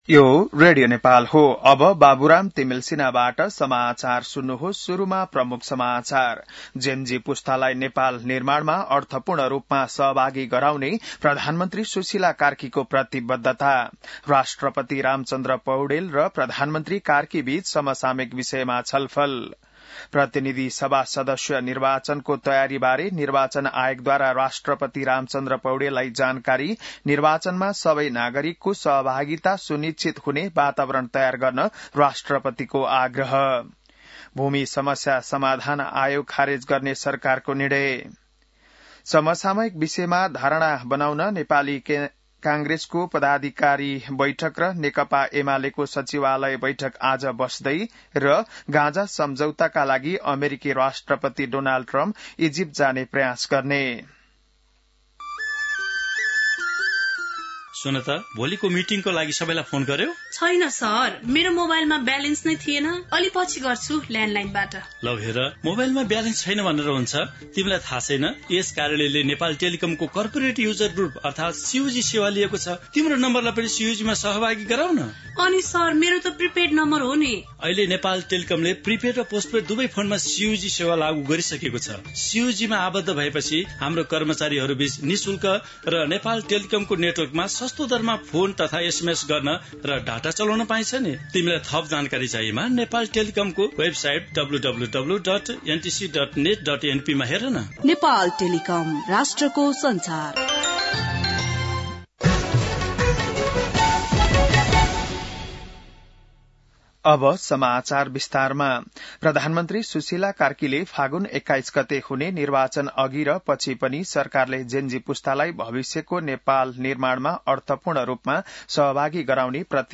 बिहान ७ बजेको नेपाली समाचार : २४ असोज , २०८२